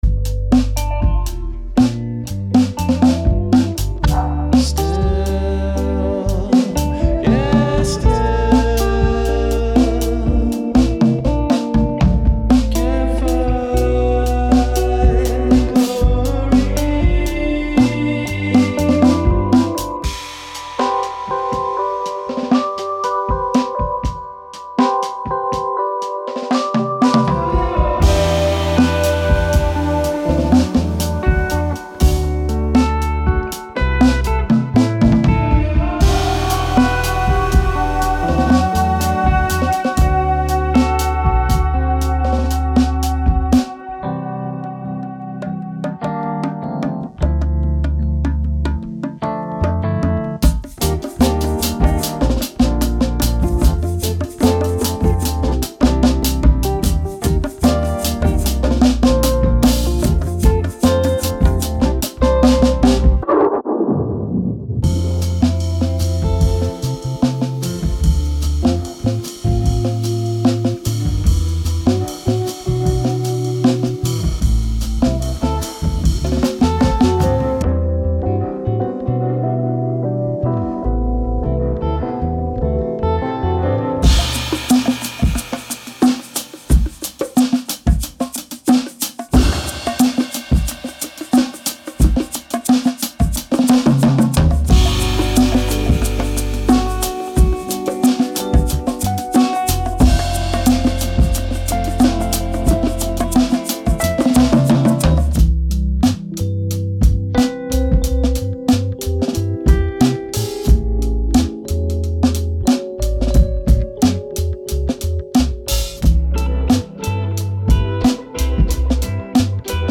Genre:Downtempo
これは、リアルタイムで捉えられたサイケデリックソウルであり、フィーリング、ニュアンス、意図が前面に出ています。
テンポは60、75、90、100、115 BPM。